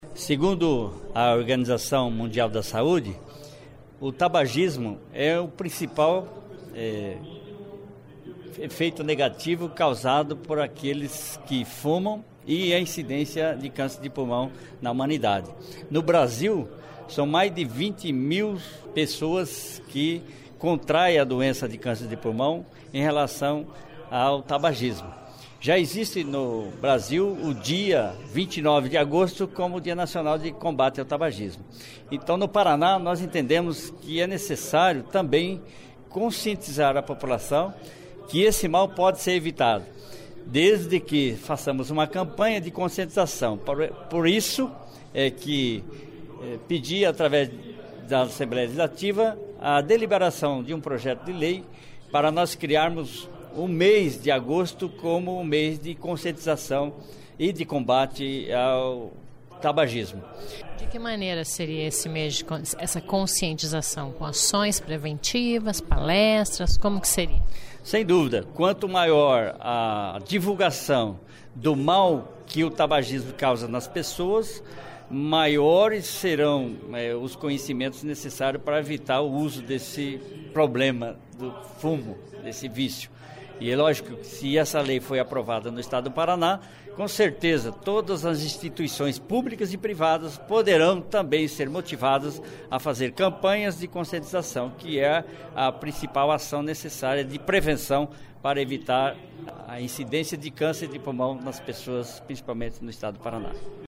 É o que prevê projeto do deputado Cláudio Palozi (PSC). Ouça entrevuista do parlamentar.